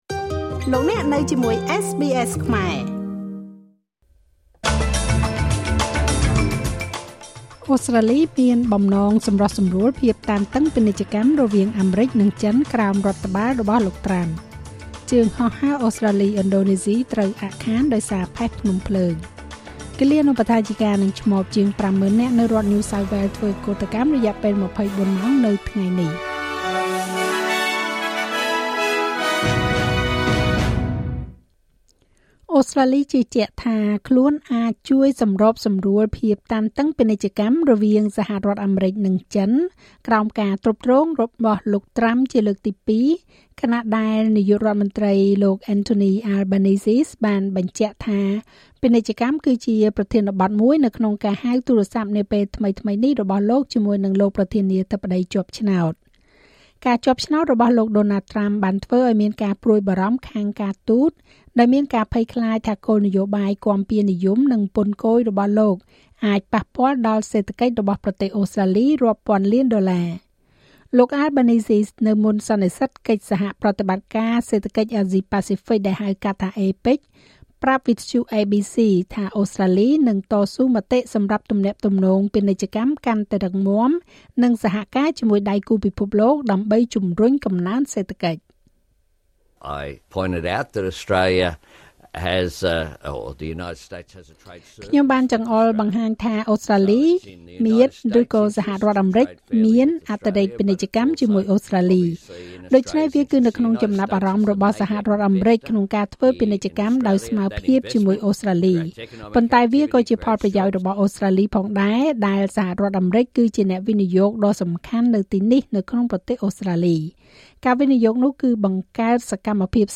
នាទីព័ត៌មានរបស់SBSខ្មែរ សម្រាប់ ថ្ងៃពុធ ទី១៣ ខែវិច្ឆិកា ឆ្នាំ២០២៤